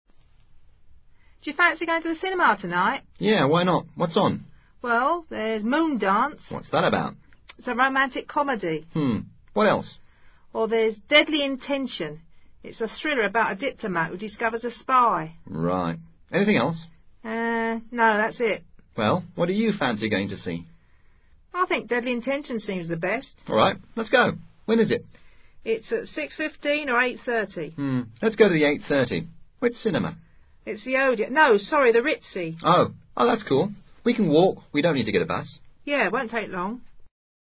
Sencillo diálogo en el que dos amigos discuten sobre la película que irán a ver.
Este diálogo en inglés se estructura según el modelo Pregunta-Respuesta.